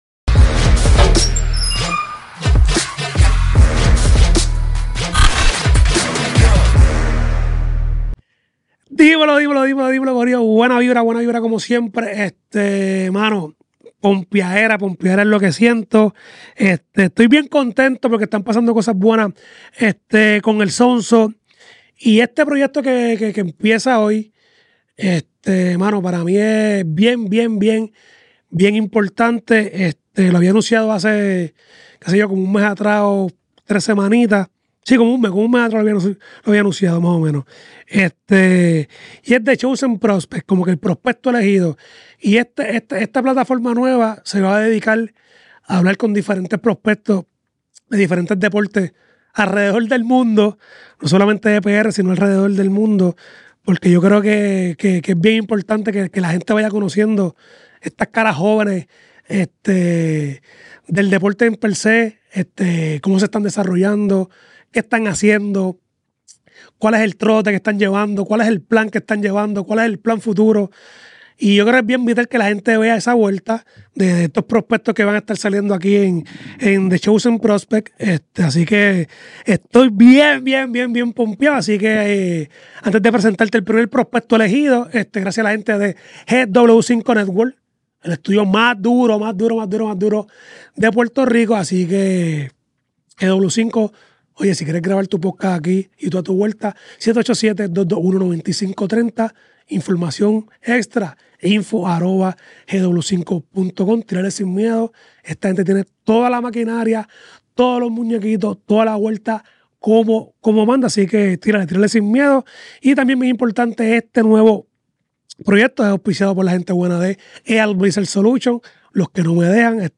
Entrevista a prospectos. Un nuevo proyecto